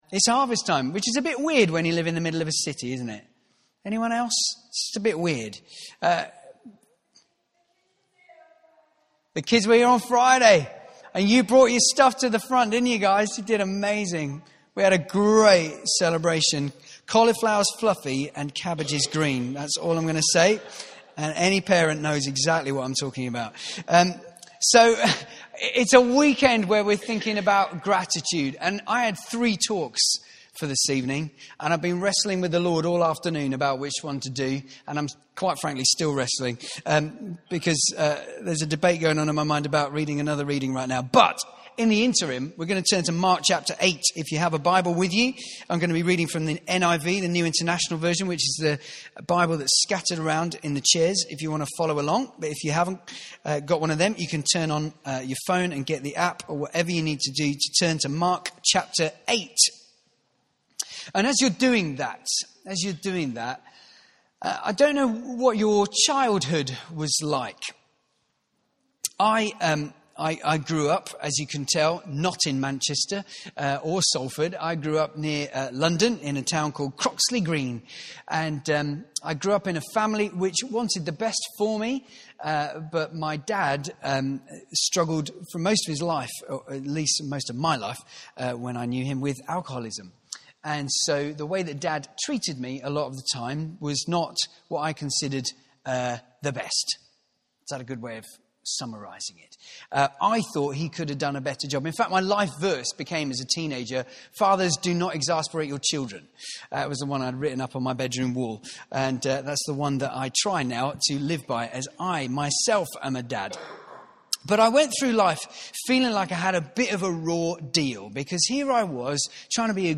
New Wine Evening Celebration - Sunday 2nd October 2016